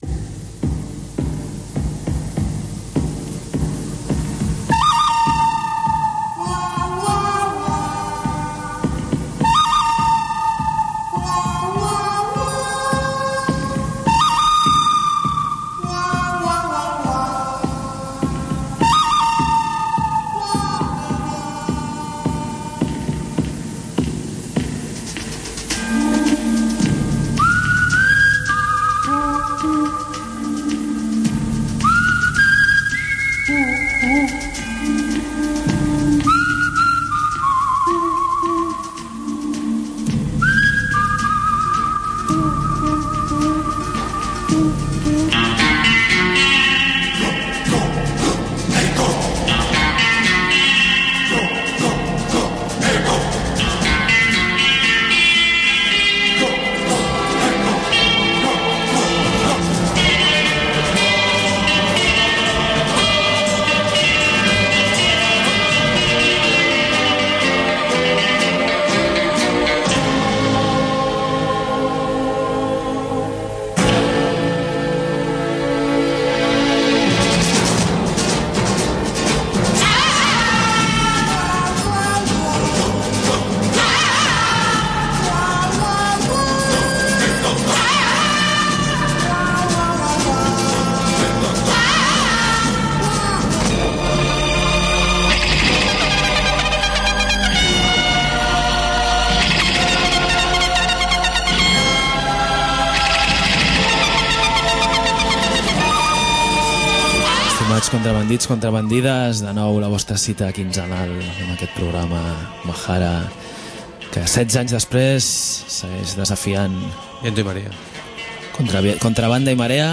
I llegim el manifest que l’Ateneu Enciclopèdic Popular ha tret reclamant la reparació del patrimoni que se li va espoliar.